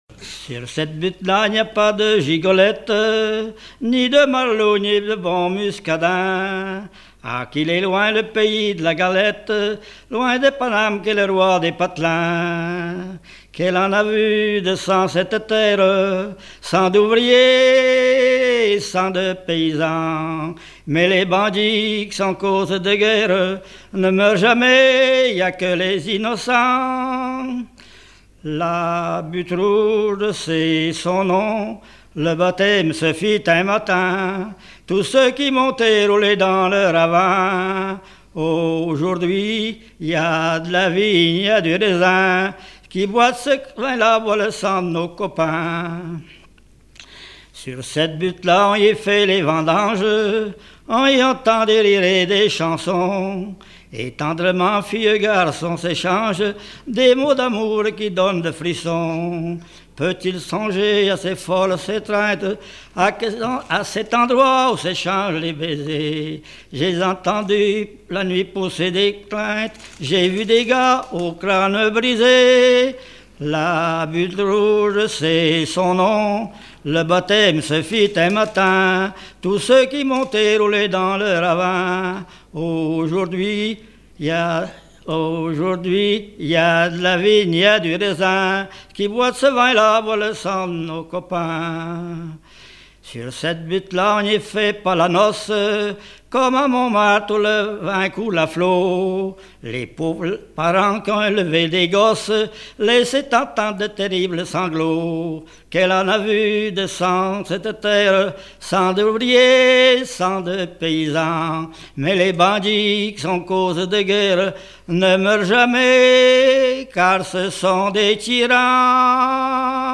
Genre strophique
regroupement de chanteurs au Vasais
Pièce musicale inédite